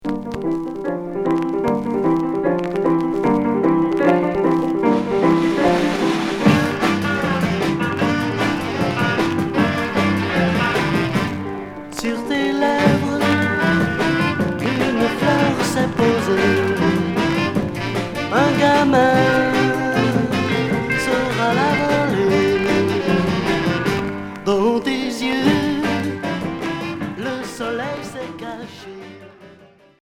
Rock 60's